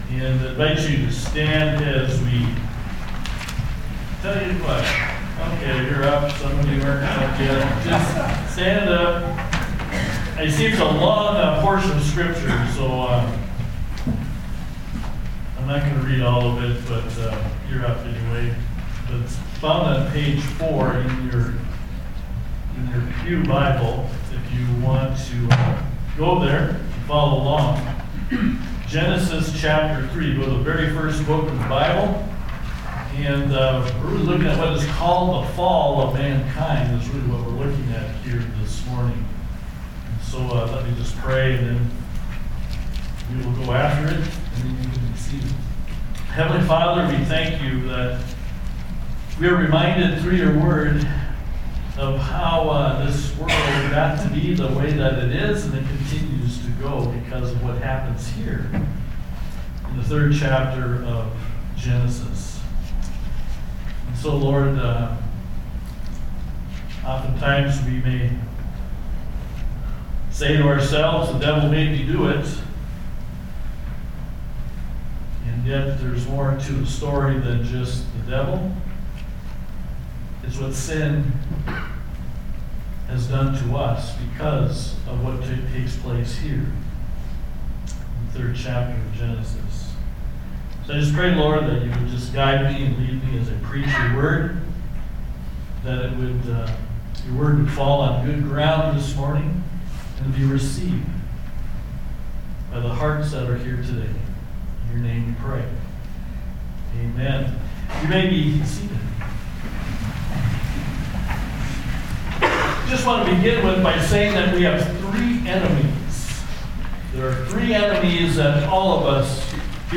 Bible Text: Genesis 3:1-21 | Preacher